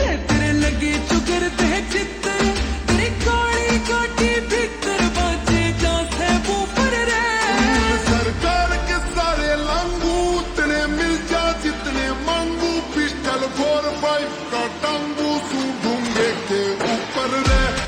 Haryanvi Songs
(Slowed + Reverb)